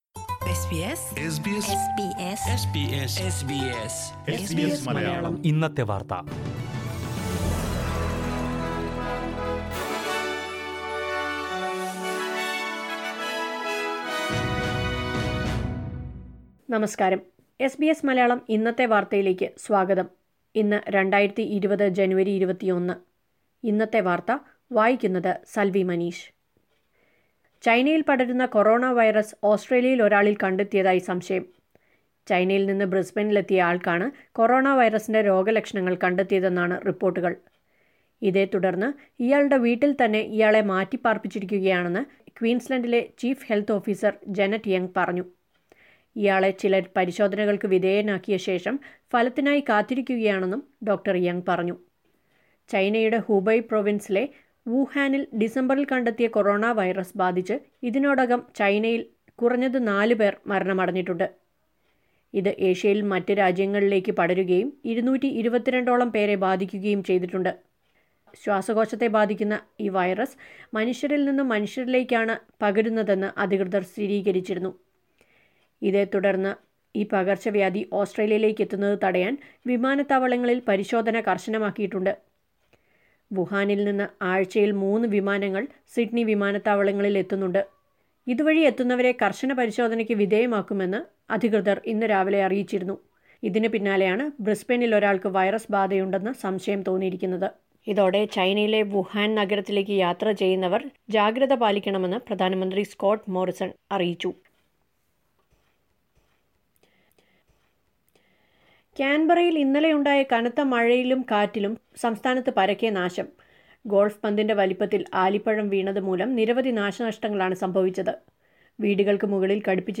2020 ജനുവരി 21ലെ ഓസ്ട്രേലിയയിലെ ഏറ്റവും പ്രധാന വാര്‍ത്തകള്‍ കേള്‍ക്കാം...
news_jan21_1.mp3